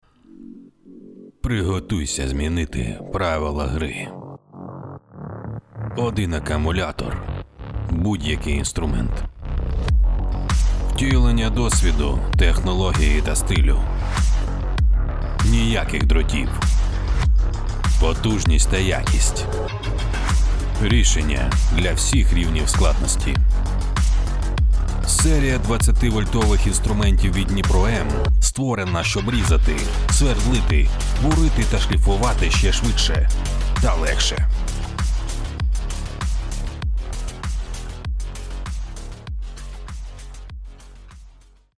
"Dnipro_M" реклама. ролик